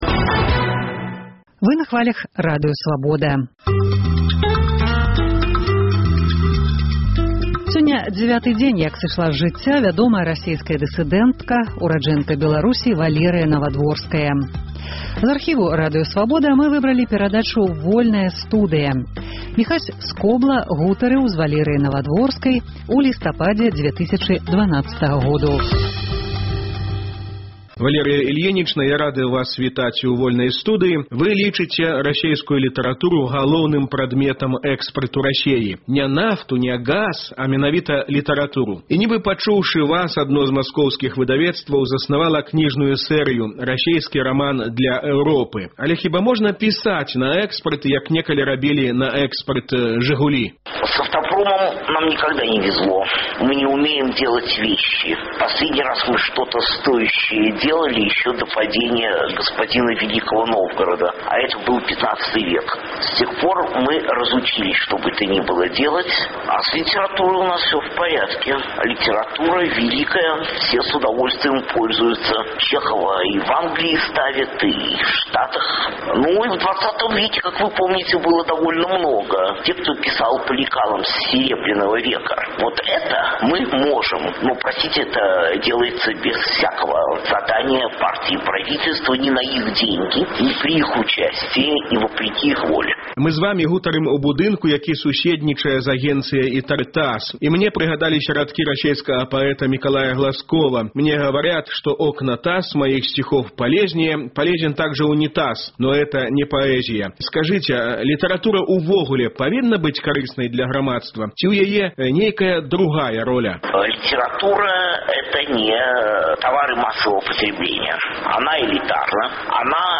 21 ліпеня спаўняецца дзевяць дзён зь дня сьмерці Валерыі Навадворскай. Паўтор перадачы «Вольная студыя» зь яе удзелам.